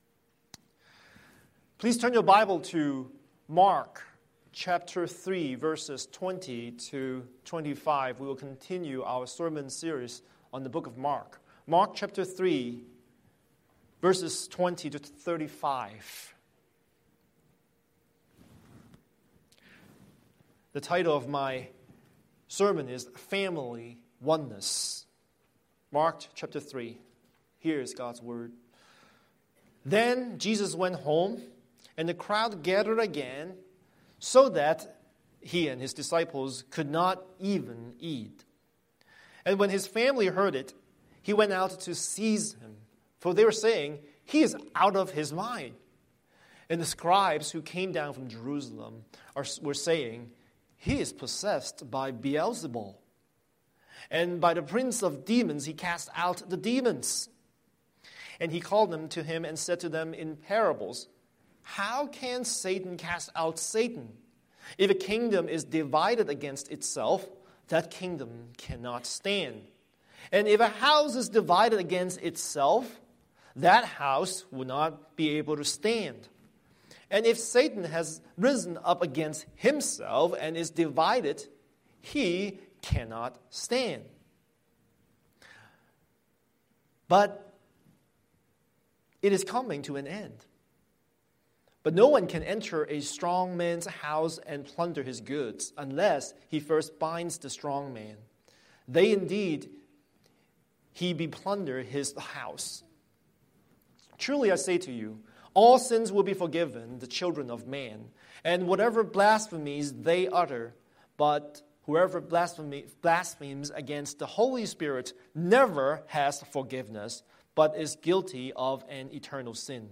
Scripture: Mark 3:20-35 Series: Sunday Sermon